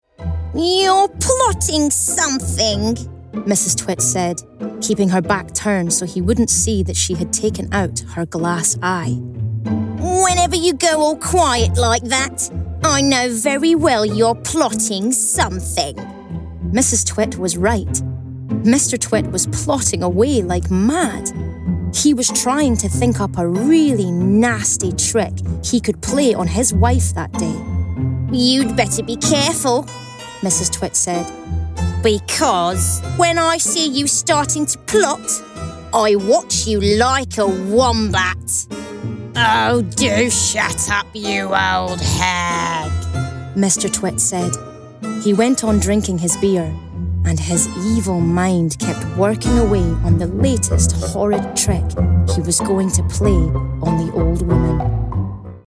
Scottish
Female
Storytelling
Warm